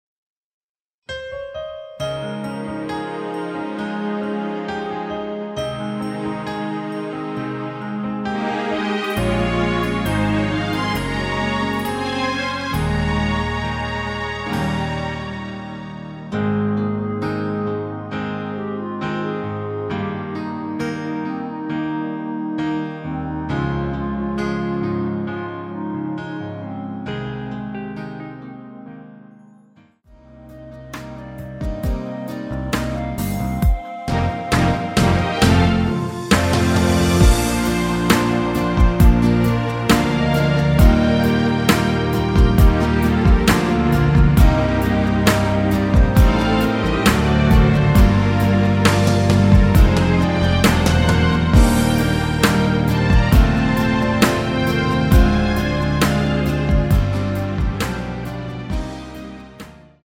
원키에서(-3)내린 멜로디 포함된 MR입니다.
Ab
멜로디 MR이라고 합니다.
앞부분30초, 뒷부분30초씩 편집해서 올려 드리고 있습니다.
중간에 음이 끈어지고 다시 나오는 이유는